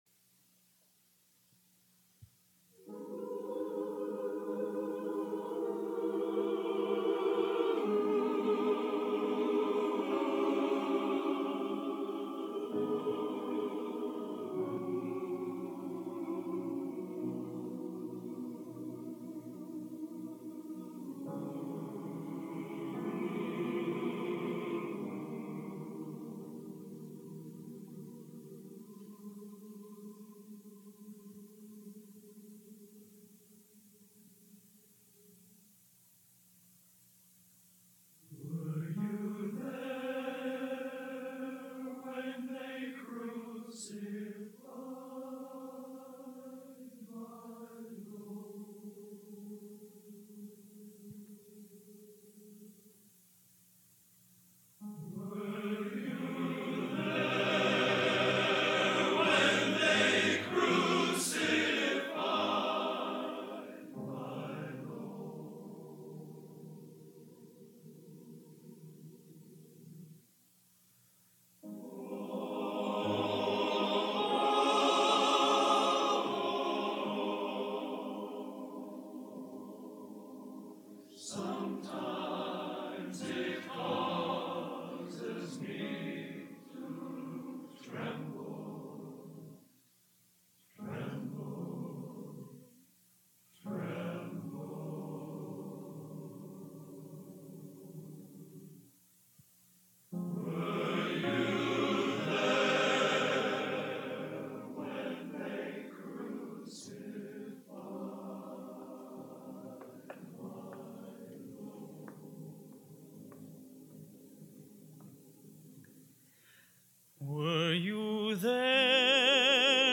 Genre: Sacred Spiritual | Type: End of Season